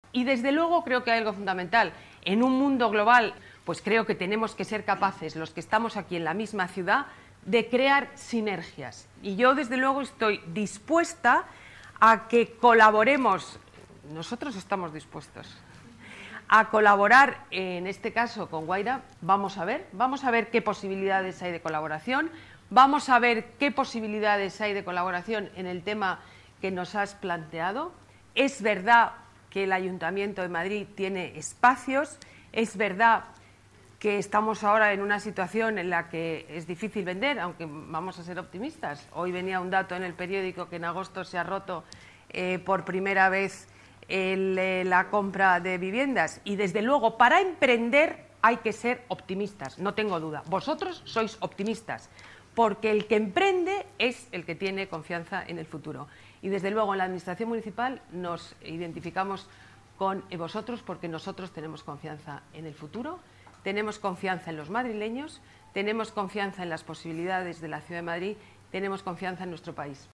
Nueva ventana:Declaraciones alcaldesa de Madrid, Ana Botella: visita academia Wayra